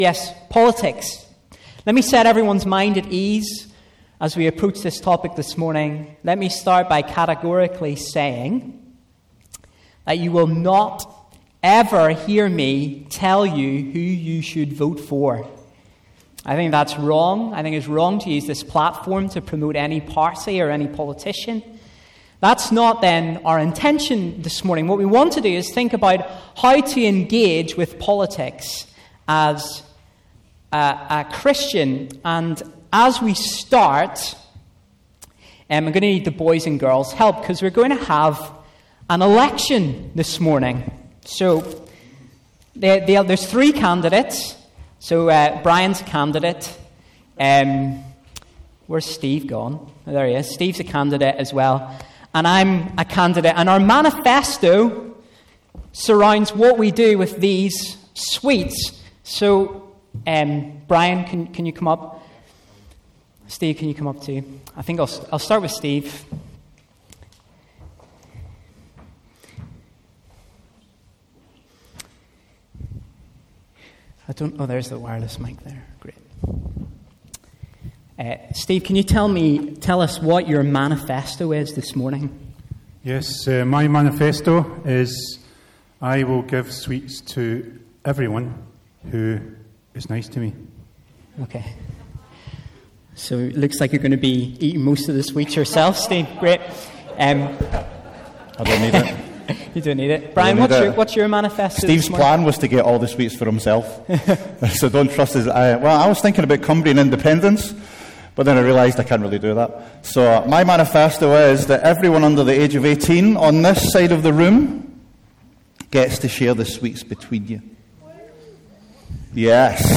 A message from the series "Pop Culture."